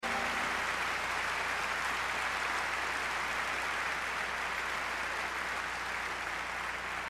Genre: Holiday | Type: Christmas Show |